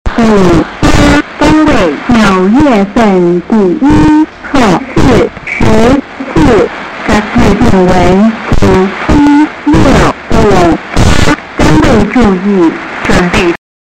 请问这是台湾的星星广播电台吗？几个星期前录得的，由于周围很吵所以录得不是很清晰。收音机是德生PL-600
口音聽得出可能來自寶島,只是兩岸現在朝向合作互信方向前進,不明白為何現在還在搞這個,